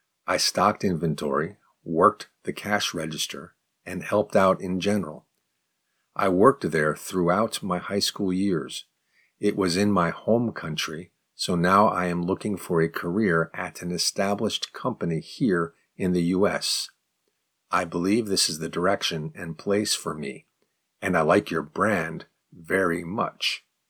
04_advanced_response_slow.mp3